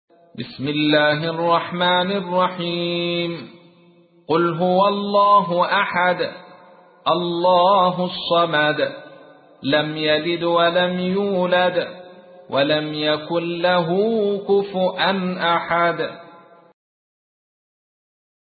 تحميل : 112. سورة الإخلاص / القارئ عبد الرشيد صوفي / القرآن الكريم / موقع يا حسين